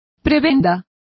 Complete with pronunciation of the translation of prebends.